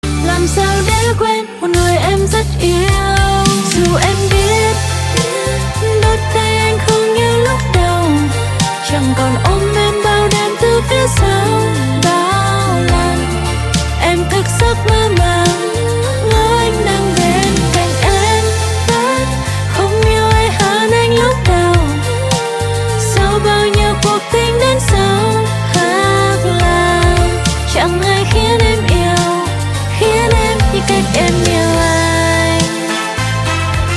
lofi